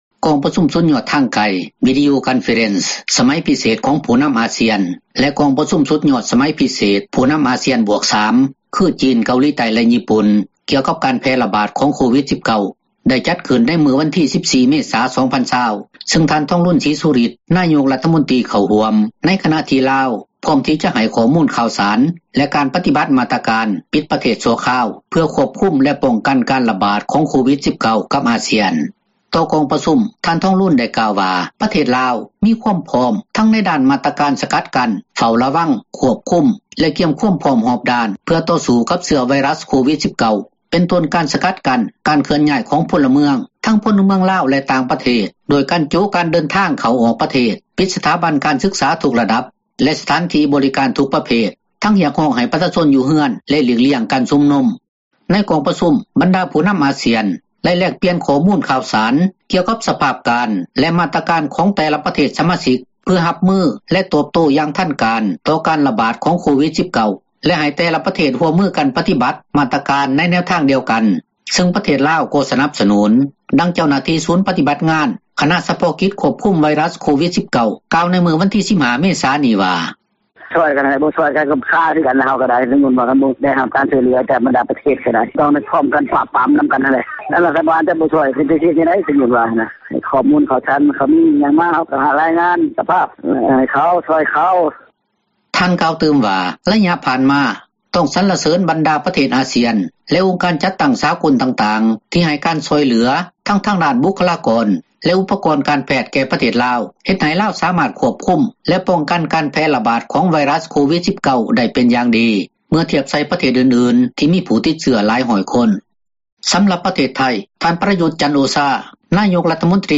ເວົ້າເຖິງກອງປະຊຸມສຸດຍອດທາງໄກ ສມັຍພິເສດ ຂອງຜູ້ນໍາອາຊຽນ +3 (ຈີນ, ເກົາຫລີໃຕ້ ແລະຍີ່ປຸ່ນ) ກ່ຽວກັບການແພ່ຣະບາດຂອງ ໂຄວິດ-19 ຊຶ່ງທັງ 13 ປະເທດ ໄດ້ມີຂໍ້ຕົກລົງຮ່ວມ ກັນ ສ້າງຕັ້ງກອງທຶນ ພິເສດ ເພື່ອຕອບໂຕ້ເຊື້ອພຍາດ ໂຄວິດ-19 ດັ່ງທີ່ທ່ານ ສເລີມໄຊ ກົມມະສິດ ຣັຖມົນຕຣີກະຊວງການຕ່າງປະເທດ ໄດ້ກ່າວໃນພິທີຖແລງຂ່າວ ຕໍ່ສື່ມວນຊົນທາງການລາວ ພາຍຫລັງກອງປະຊຸມ ໃນມື້ວັນທີ 14 ເມສານີ້ວ່າ: